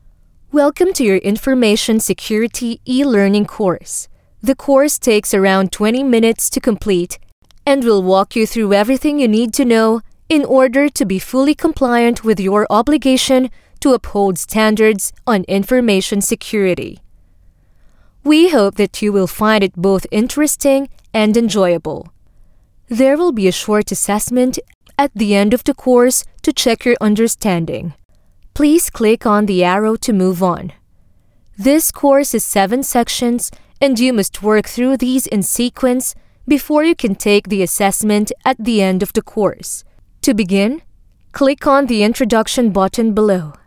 PH ENGLISH FEMALE VOICES
female